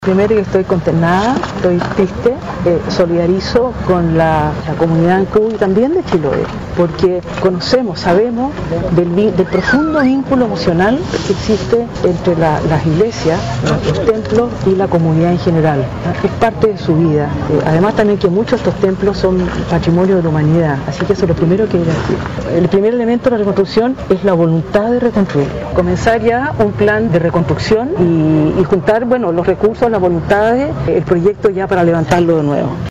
Por su parte la ministra Consuelo Valdés, del Ministerio de la Cultura, las Artes y el Patrimonio, junto con manifestar su pesar por el ataque incendiario a la parroquia San Francisco de Ancud, señaló que por tratarse de un inmueble perteneciente a monumento nacional, el Estado destinará recurso a la brevedad para su reconstrucción.